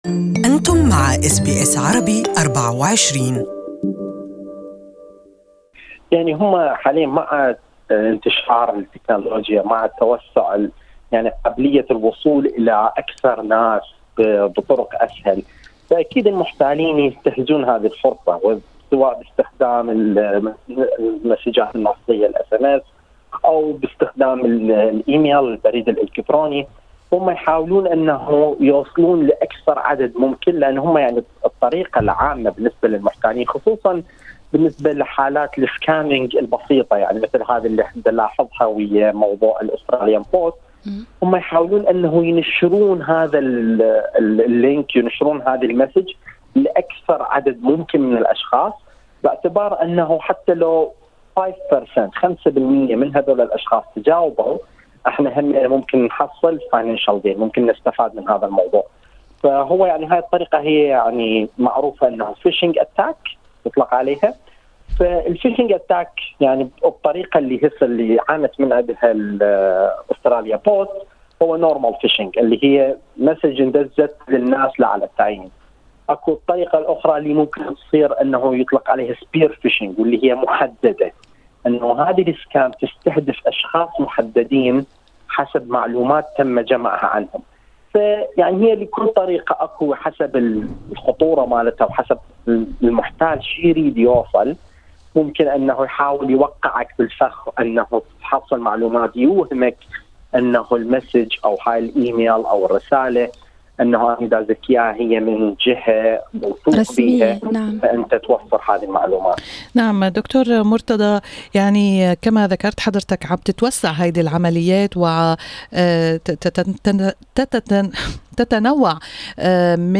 لقاءِ مباشر